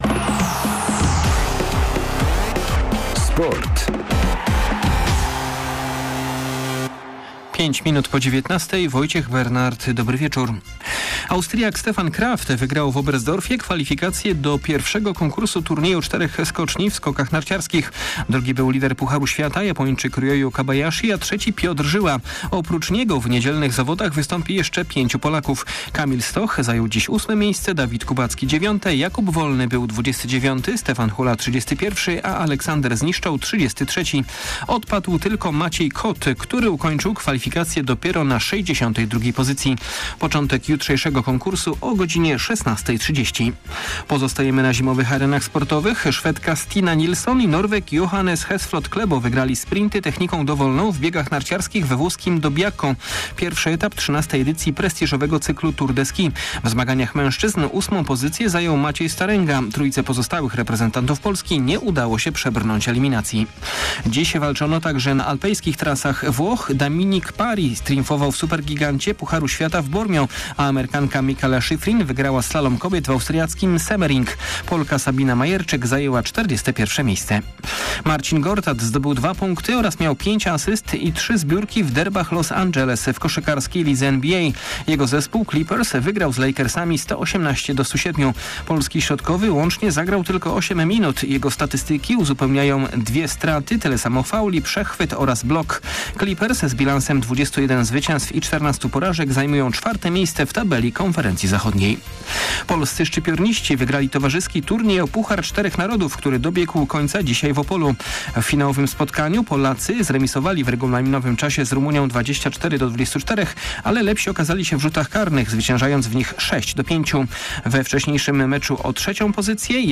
29.12. SERWIS SPORTOWY GODZ. 19:05